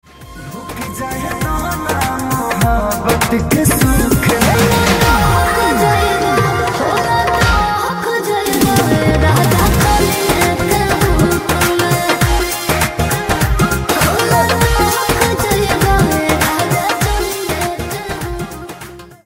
Bhojpuri Song
The infectious rhythm and upbeat tempo